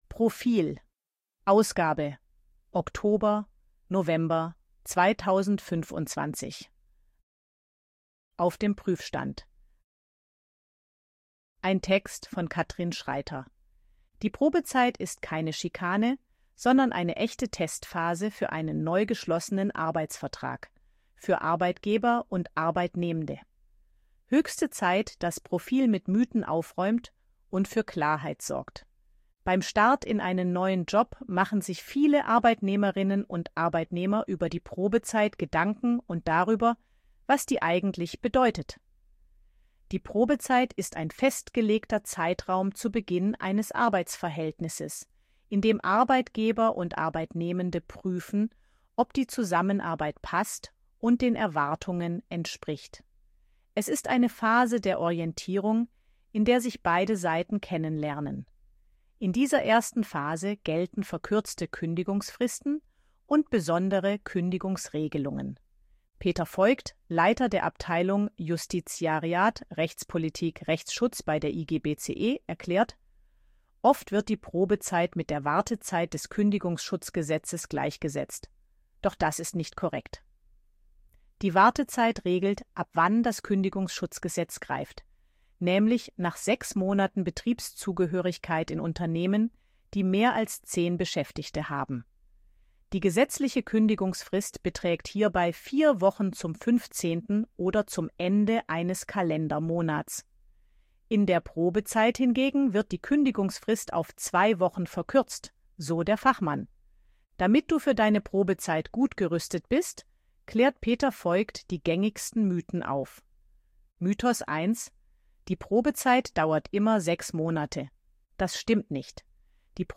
ElevenLabs_255_KI_Stimme_Frau_Service_Arbeit.ogg